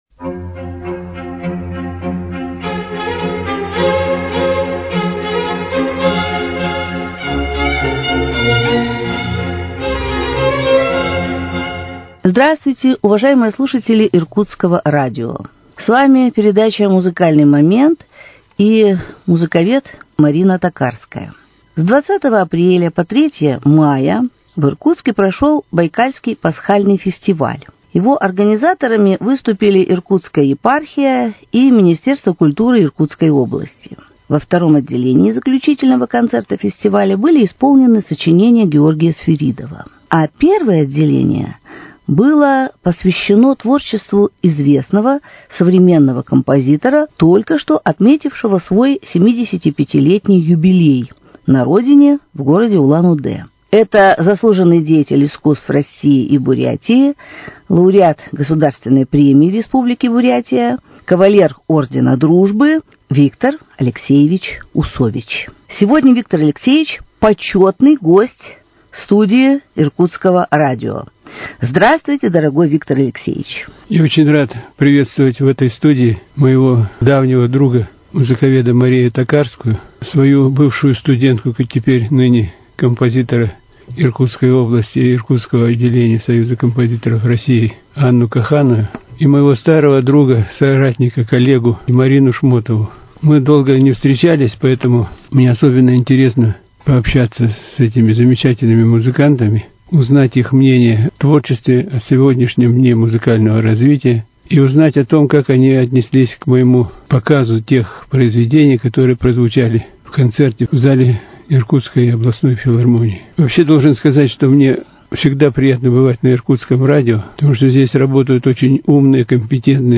Культура